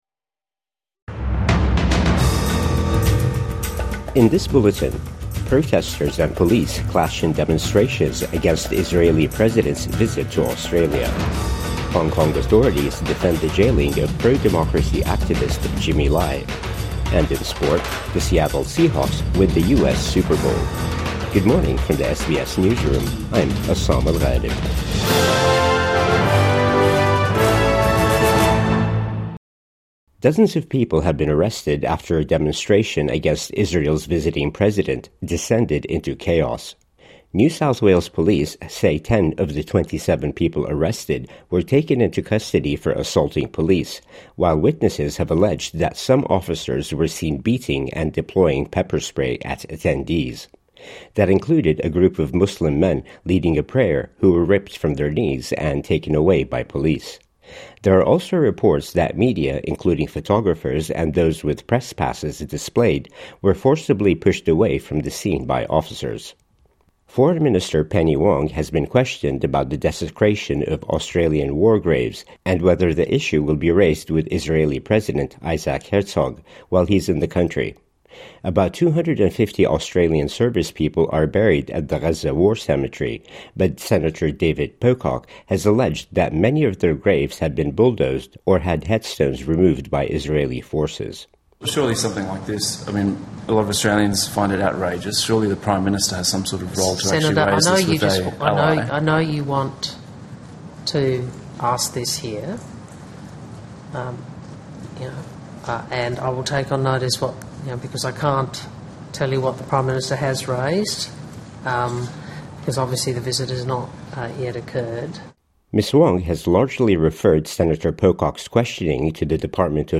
Police pepper spray, arrest Herzog demonstrators at Sydney Town Hall | Morning News Bulletin 10 February 2026